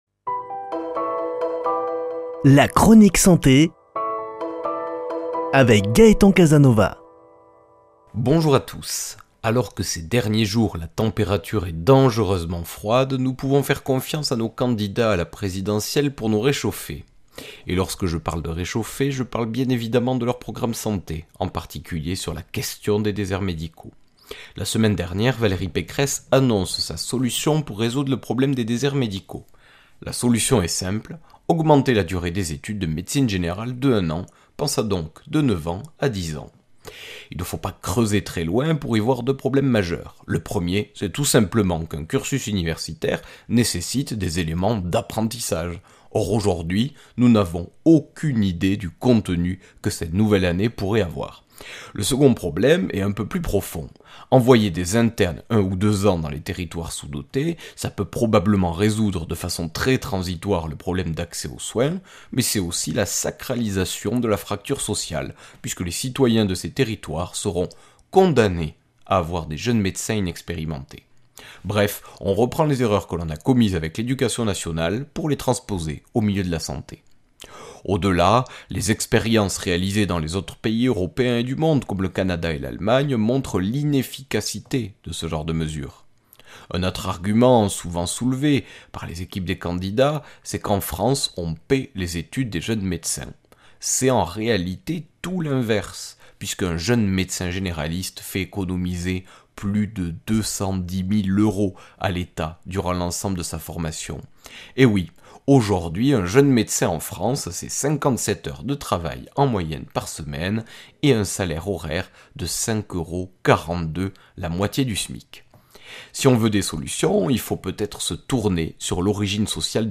Chronique santé